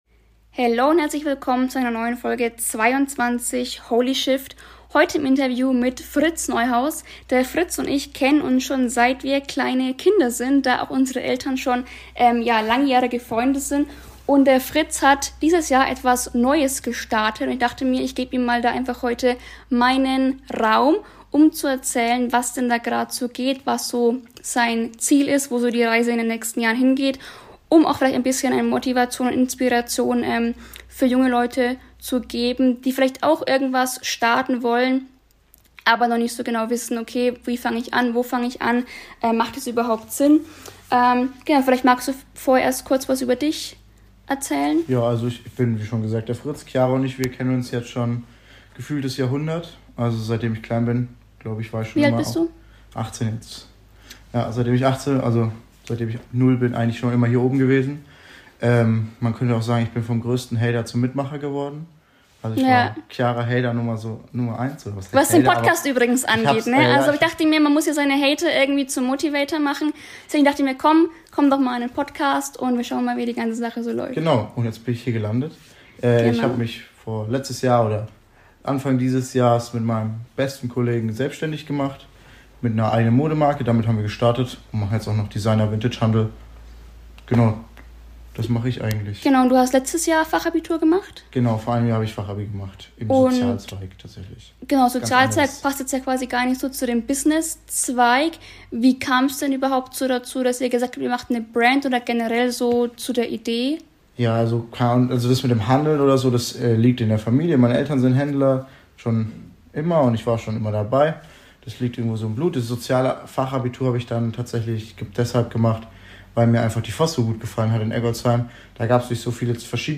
Es geht um Unternehmertum, Mindset, Haltung – und um die Erkenntnis, dass man nicht immer einer Meinung sein muss, um sich gegenseitig zu bereichern. Ein ehrlicher Talk zwischen zwei Perspektiven.